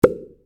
bump.wav